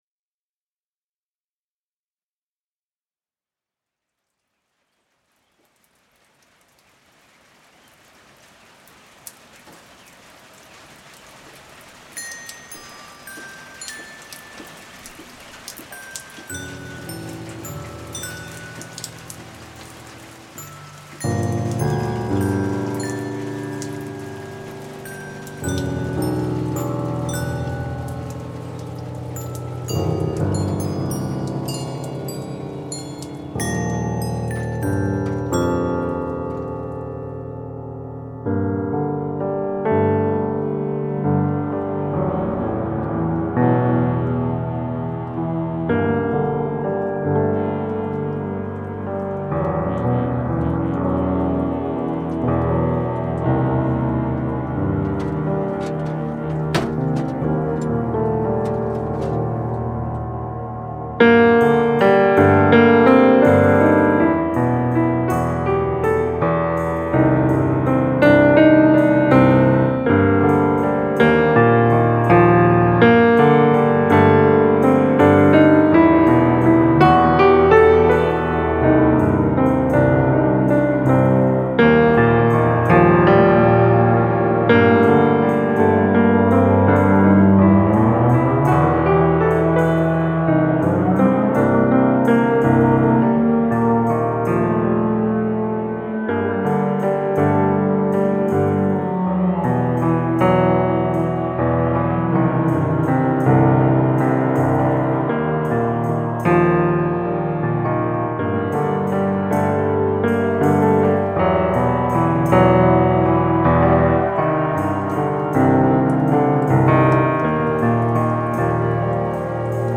Download sintetizador piano_1 pandereta Channels